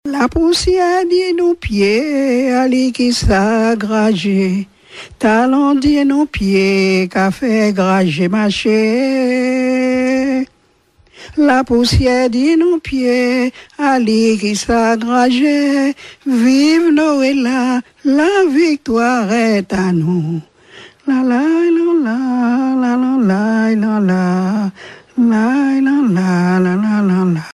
Mémoires et Patrimoines vivants - RaddO est une base de données d'archives iconographiques et sonores.
danse : grajé (créole)
Pièce musicale inédite